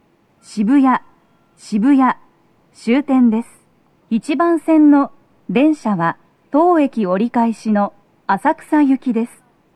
スピーカー種類 TOA弦型、TOA天井型() ※収録音声は全てTOA弦型での収録。
🎵到着放送
駅員放送が被りやすく、多少の粘りが必要です。
女声